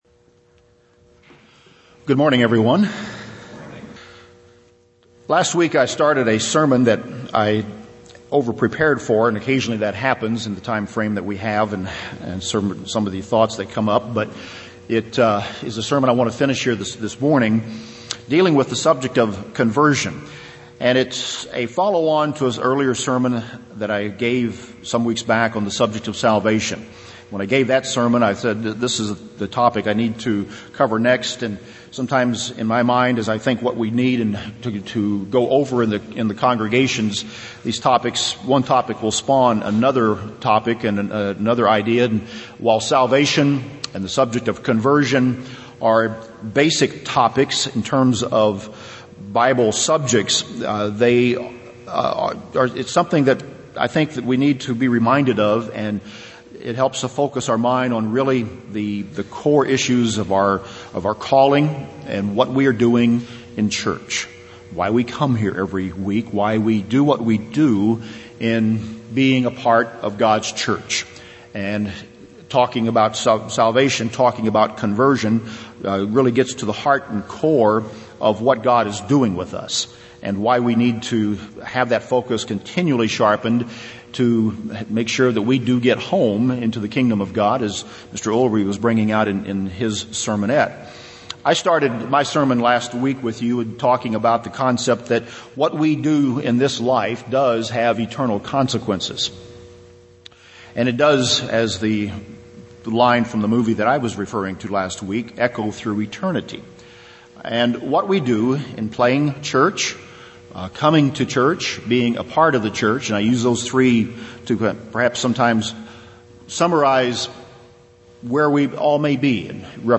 The continuation of last week's Sermon on conversion and why God chose to make it such a difficult and life-long process.